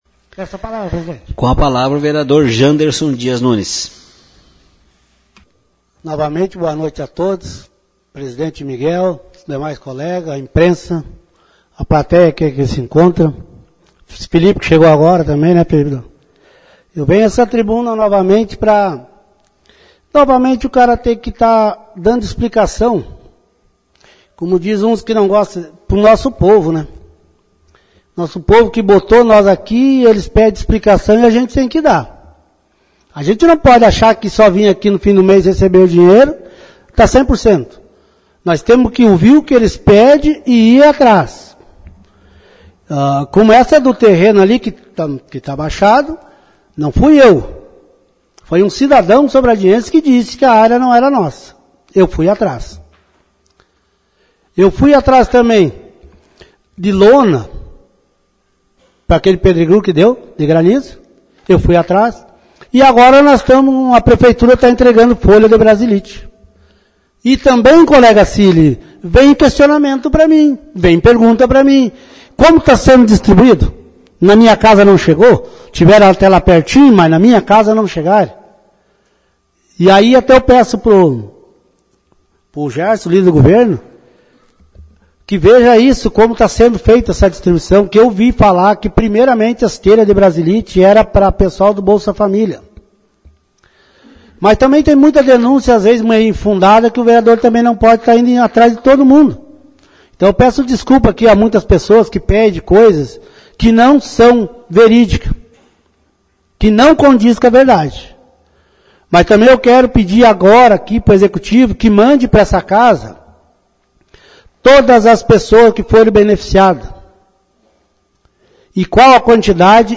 Confira os pronuncimentos na tribuna: